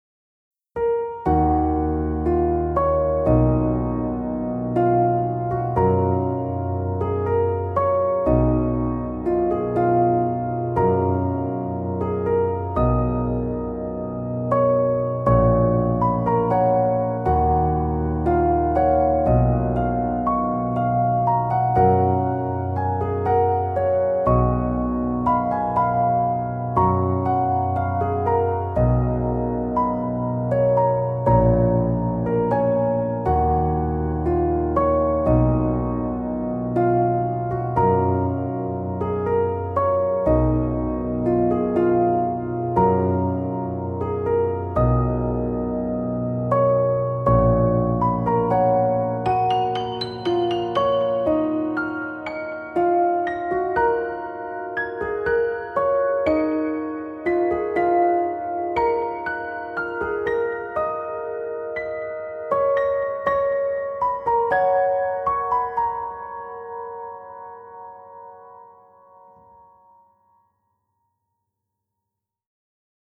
PIANO H-P (34)